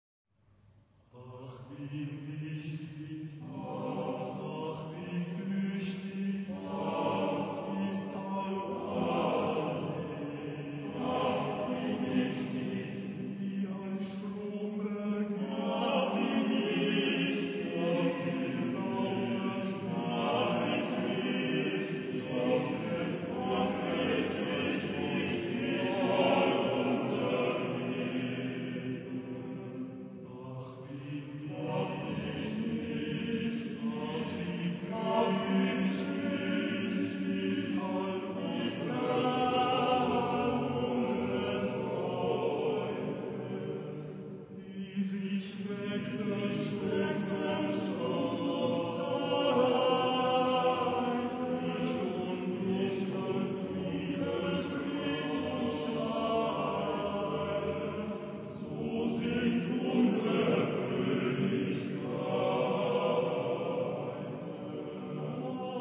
Género/Estilo/Forma: Romántico ; Sagrado ; Motete
Tonalidad : do menor